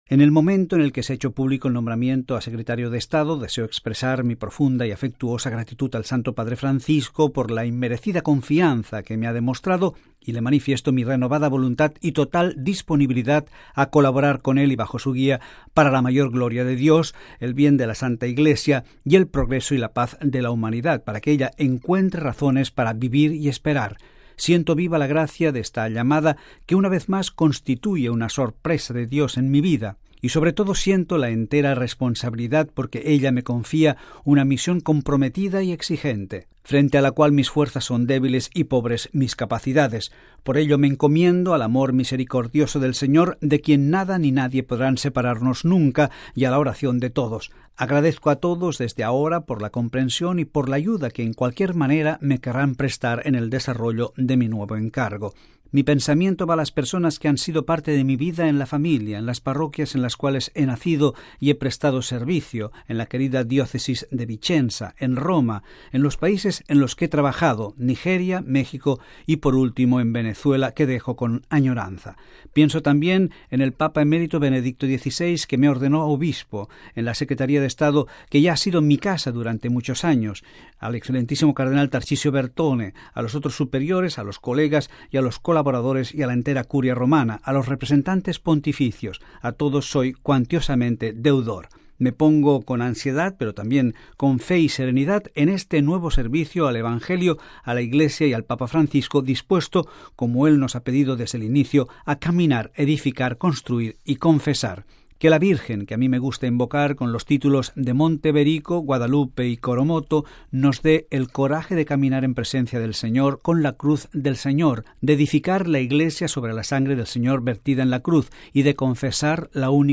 (RV).- Mons. Pietro Parolin, después del nombramiento a Secretario de Estado, ha concedido en Venezuela la siguiente declaración: